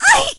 shelly_hurt_01.ogg